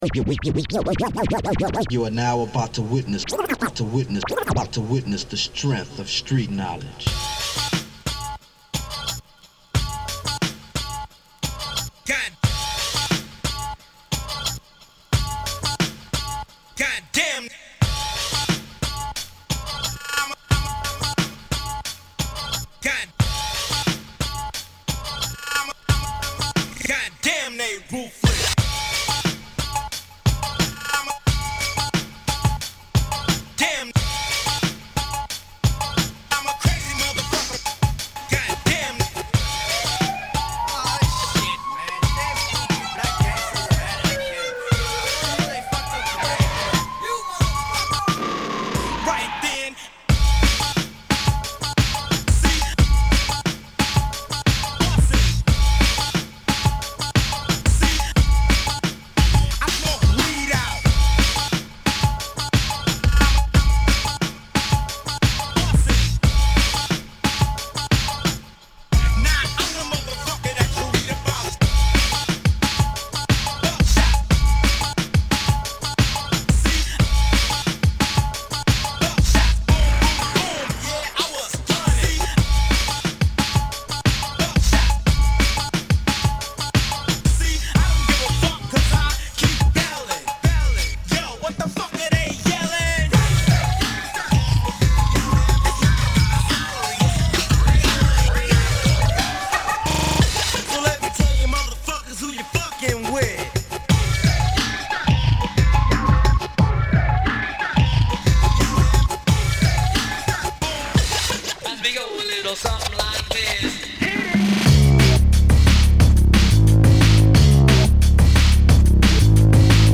Download: Aquarius Roller Rink Mix Practice
actually pulled out real records and went back to the turntables